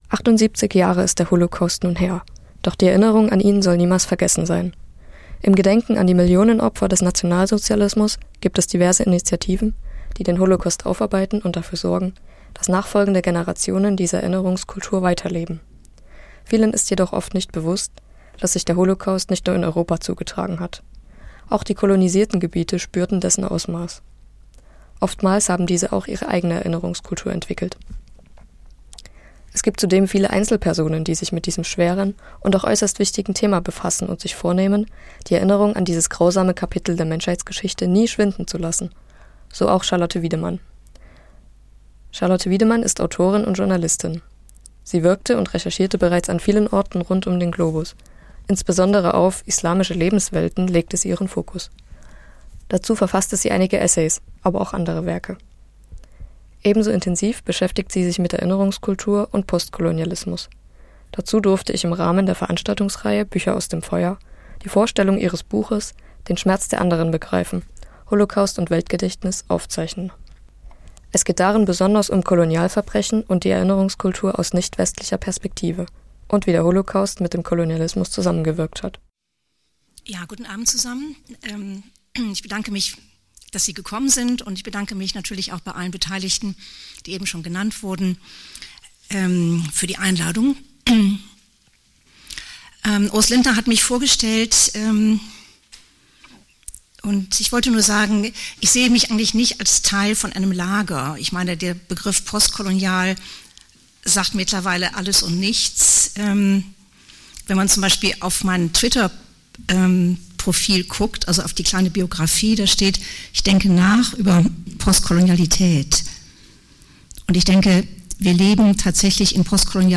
Radio F.R.E.I. hat die Veranstaltung aufgezeichnet und die anschließende Diskussion zusammengefasst.